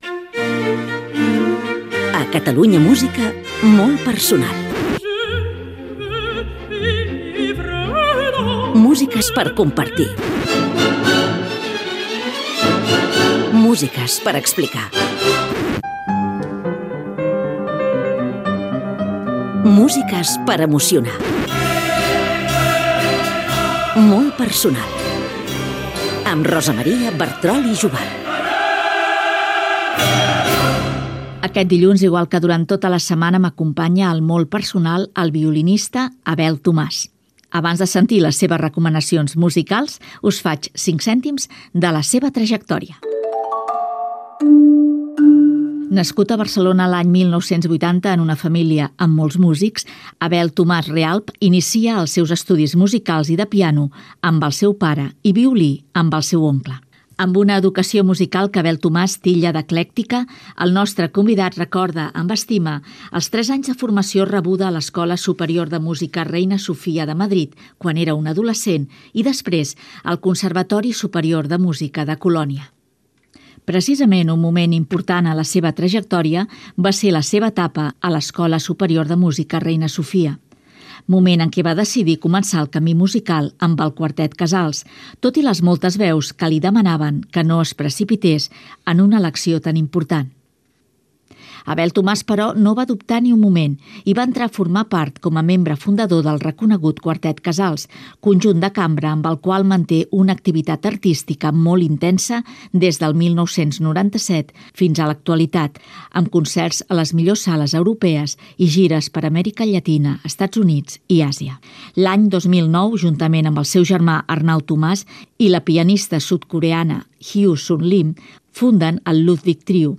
Careta del programa
Musical